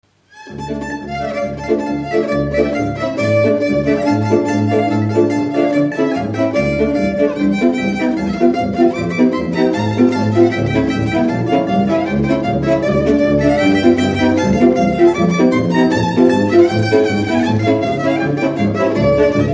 Dallampélda: Hangszeres felvétel
Alföld - Pest-Pilis-Solt-Kiskun vm. - Bogyiszló
hegedű
tambura (prím)
brácsa
bőgő
Műfaj: Kanásztánc
Stílus: 7. Régies kisambitusú dallamok
Kadencia: 1 (1) b3 1